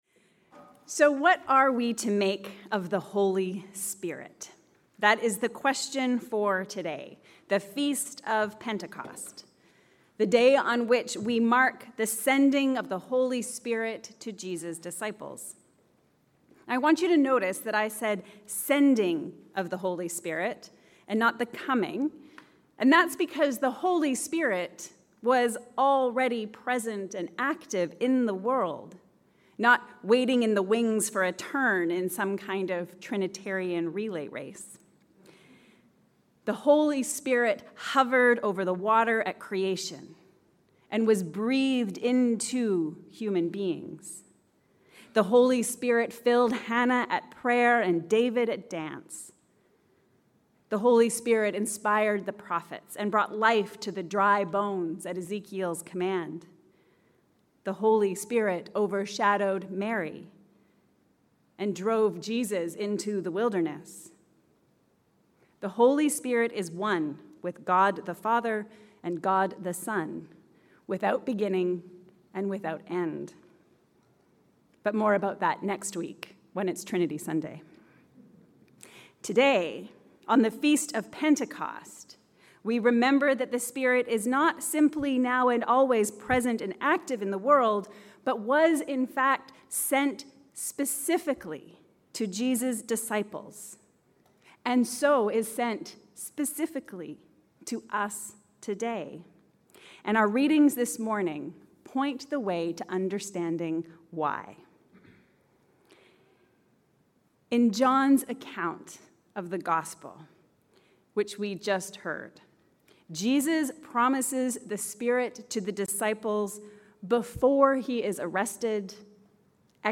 Holy Spirit -Advocate, Counsellor, Comforter. A Sermon for Pentecost Sunday